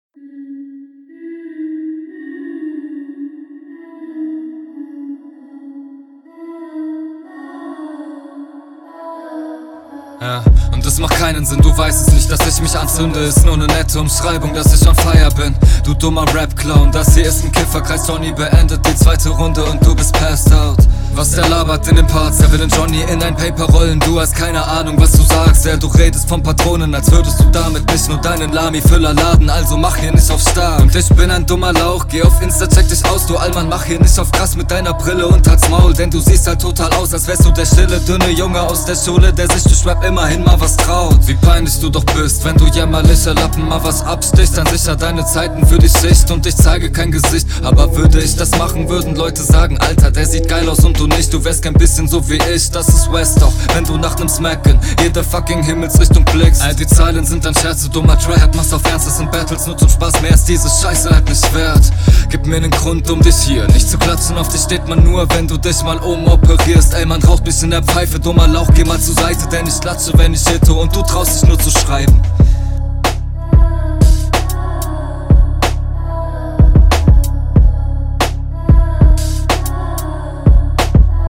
Flow: Uhhh schön gestylt. Flowtechnisch seid ihr beide stark.